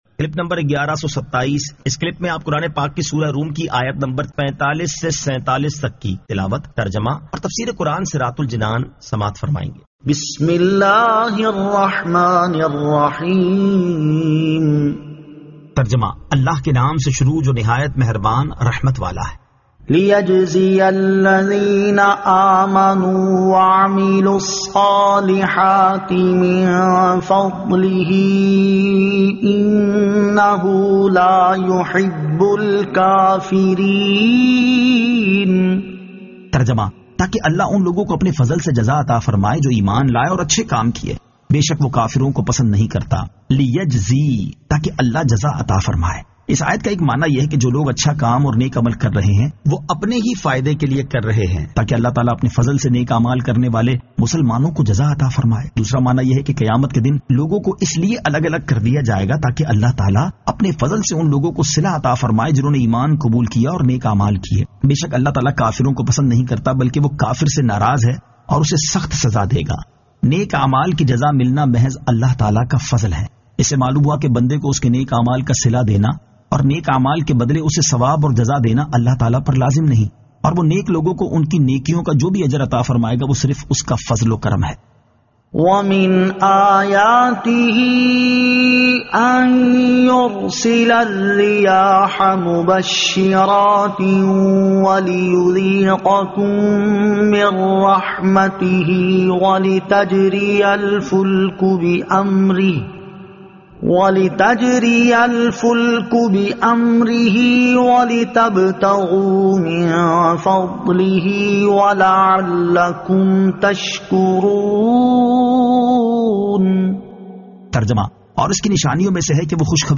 Surah Ar-Rum 45 To 47 Tilawat , Tarjama , Tafseer